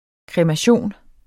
Udtale [ kʁεmaˈɕoˀn ]